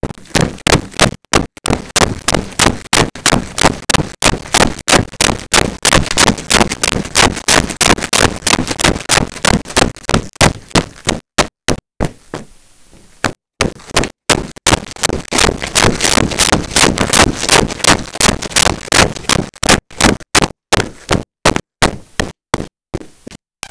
Footsteps Run Hard Floor 02
Footsteps Run Hard Floor 02.wav